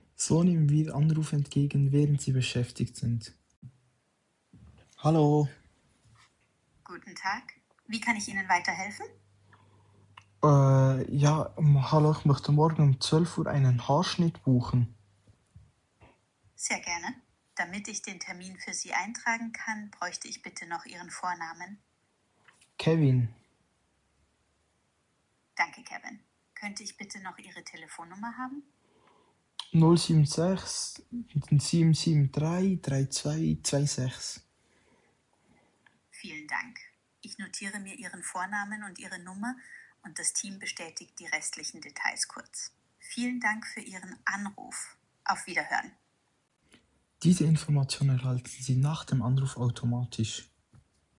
AI receptionist for your shop that answers incoming calls and sends you the details.